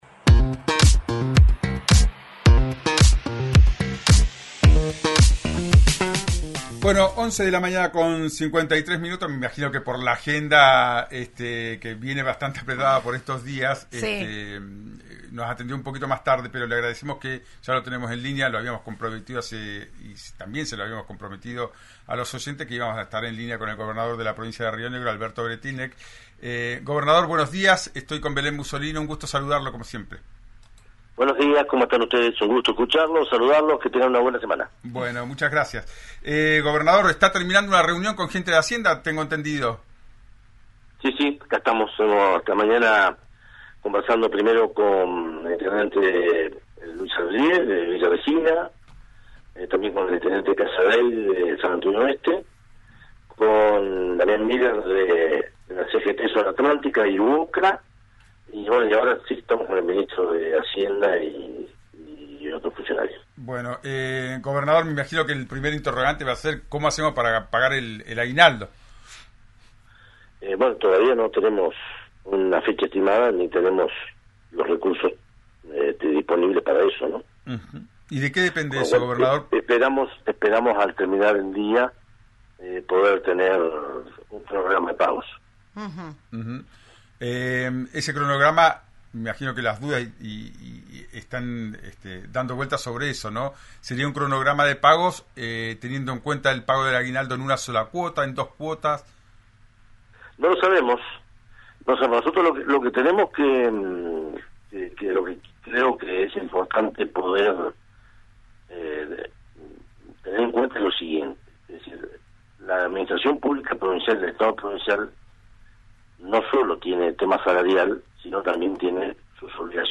Esta mañana, el Weretilneck, en diálogo con RÍO NEGRO RADIO, habló sobre la designación de San Martín y qué pretende con el nombramiento.